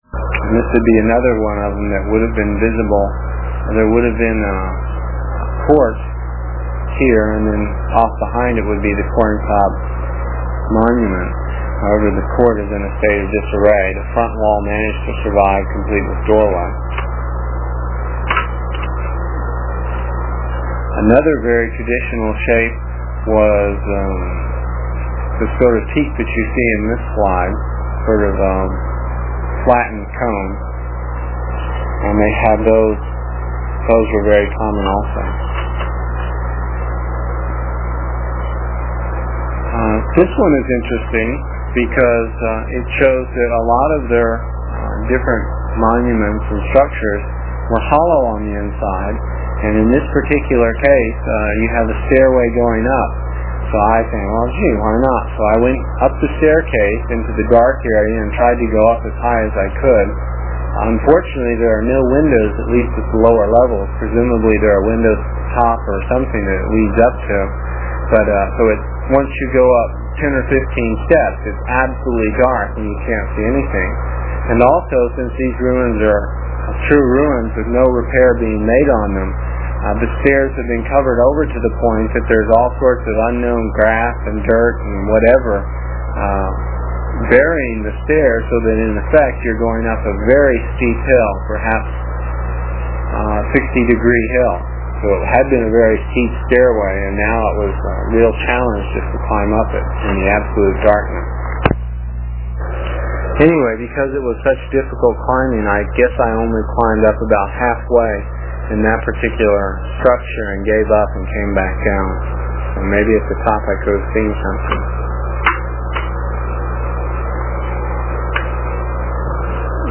voice description of each slide that you can listen to while looking at the slides. It is from the cassette tapes we made almost thirty years ago. I was pretty long winded (no rehearsals or editting and tapes were cheap) and the section for this page is about five minutes and will take about two minutes to download with a dial up connection.